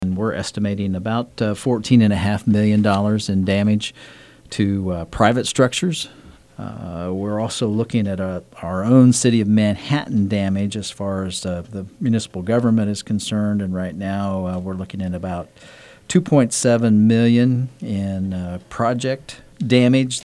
Manhattan City Manager Ron Fehr was on Tuesday’s edition of KMAN’s In Focus and said the flash flooding caused $17.2 million in damage.